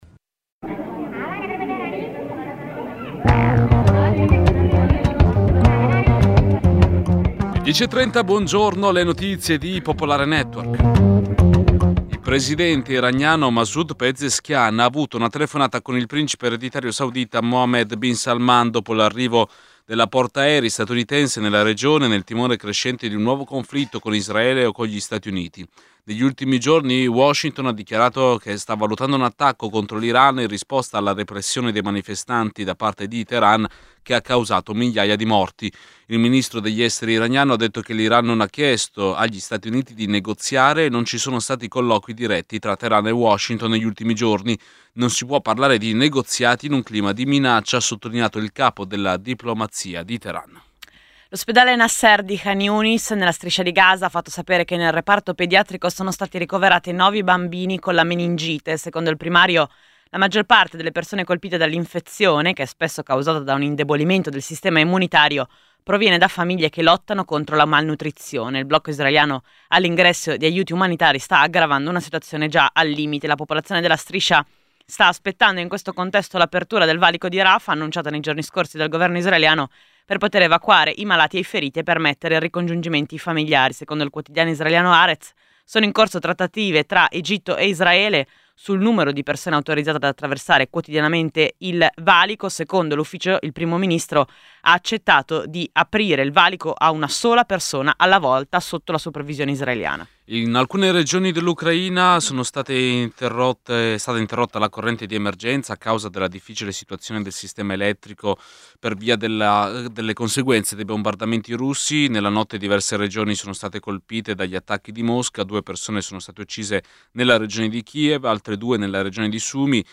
Giornale radio
Annunciati dalla “storica” sigla, i nostri conduttori vi racconteranno tutto quello che fa notizia, insieme alla redazione, ai corrispondenti, agli ospiti.